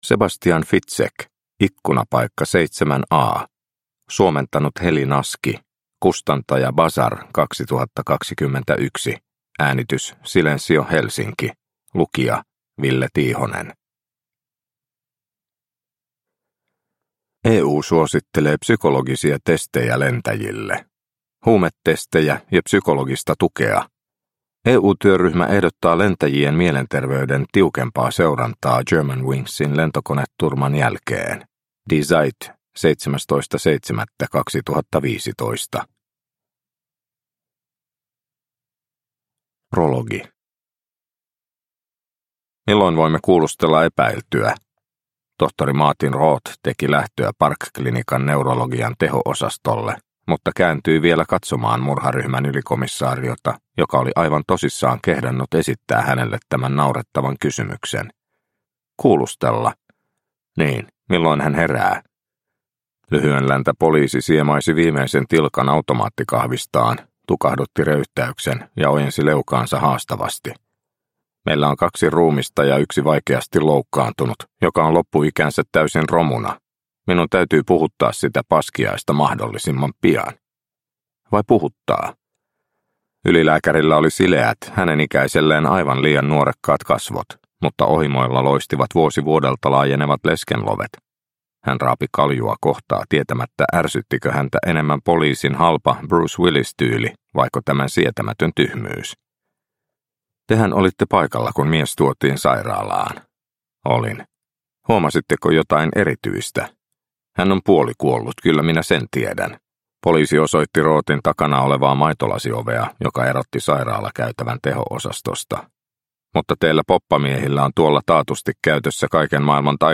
Ikkunapaikka 7A – Ljudbok – Laddas ner